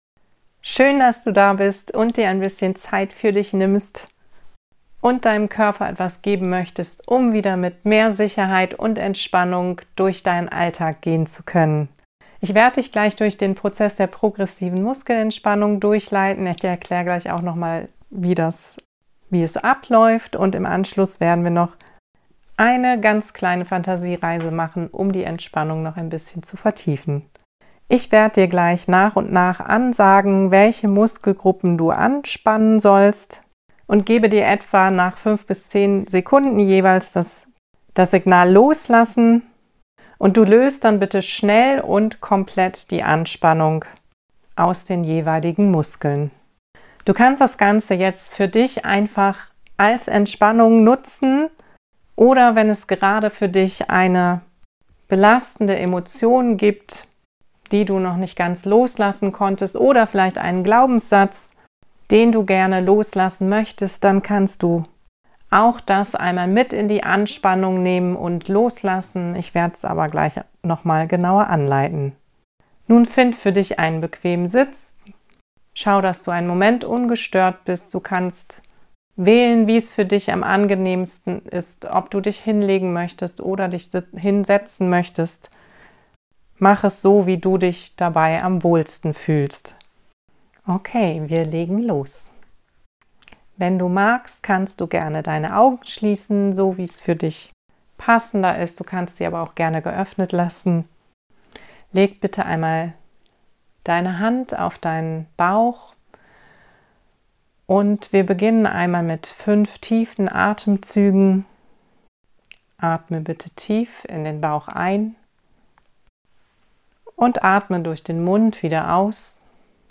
Hilf deinem Körper Stress und Anspannung loszulassen, in dem du bewusst anspannst und loslässt. Ich begleite dich durch den Prozess der progressiven Muskelentspannung.